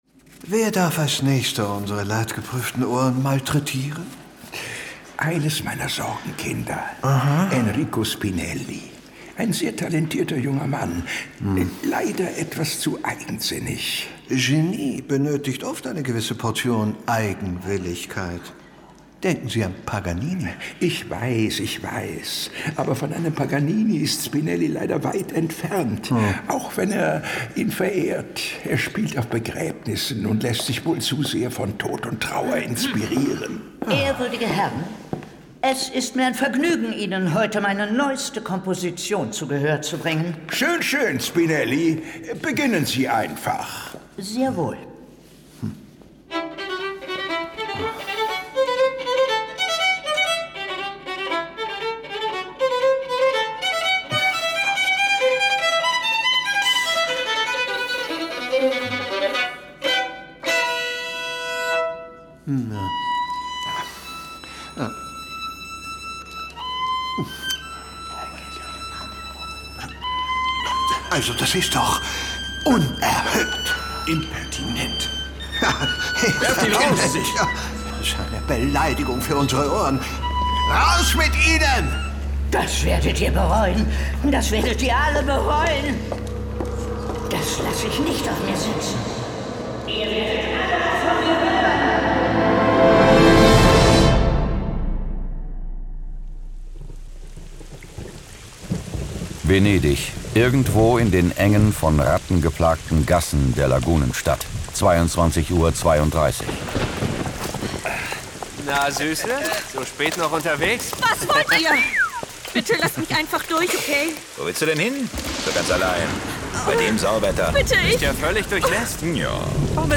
John Sinclair Classics - Folge 34 Die Todesgondel. Hörspiel.